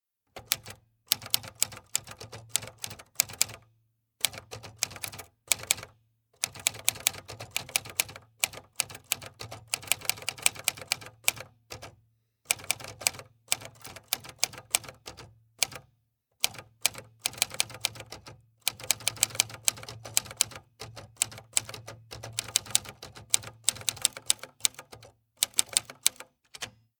type.mp3